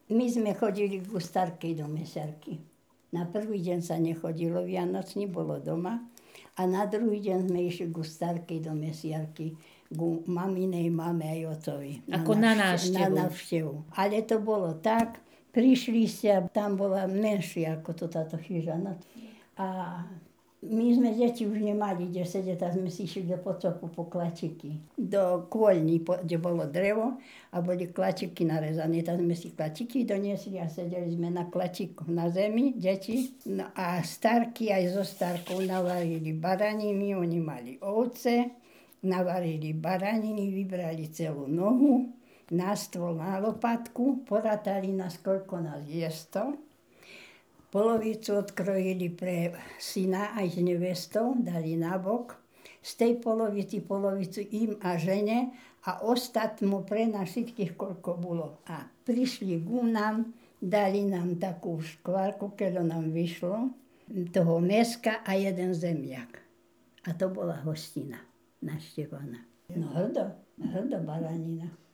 Miesto záznamu Polomka